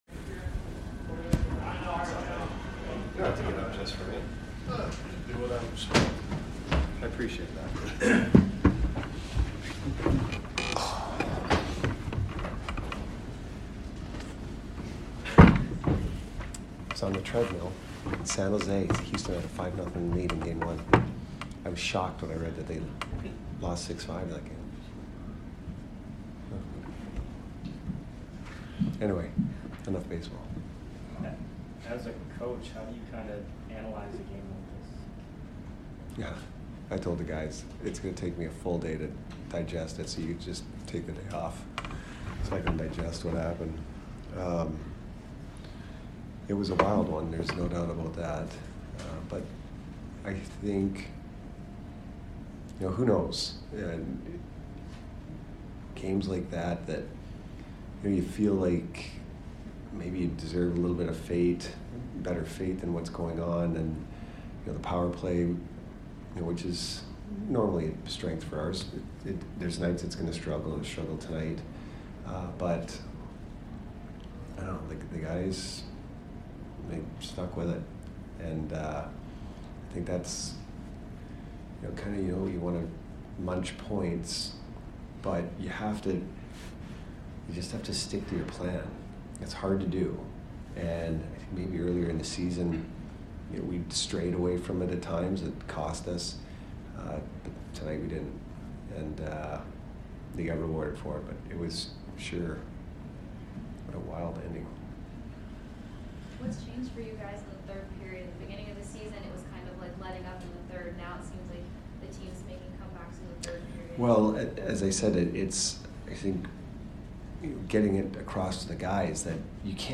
Head Coach Jon Cooper Post Game 11/1/22 vs. OTT